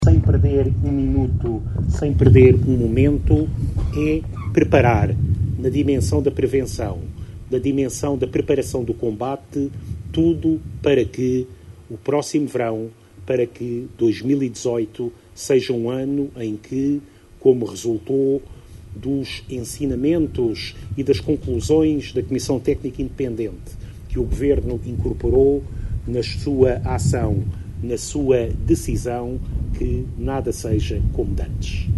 A visita, que contou também com a presença do Ministro da Administração Interna, Eduardo Cabrita, iniciou-se na freguesia de Ancora, seguindo depois para Riba de Âncora onde teve lugar a cerimónia de assinatura do protocolo para a contratação e funcionamento das equipas de intervenção permanente que irão ficar sediadas nas corporações de bombeiros de Caminha e Vila Praia de Âncora.